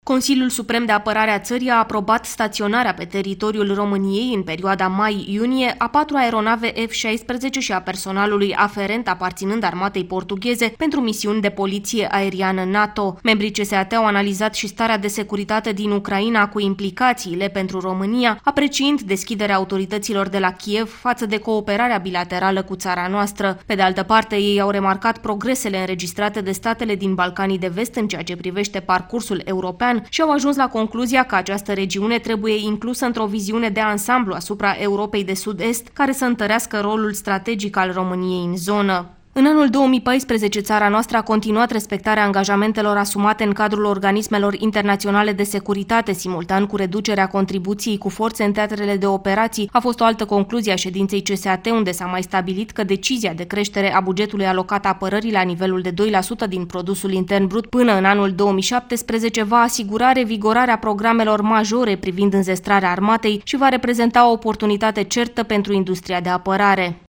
Relatează reporterul RRA